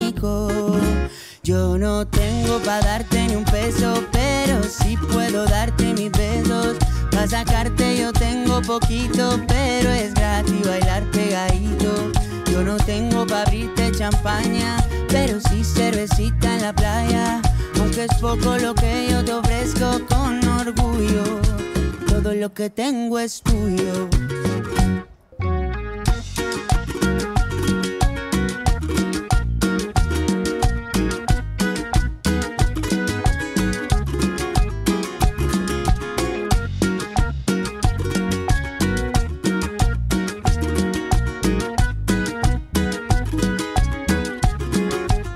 cumbia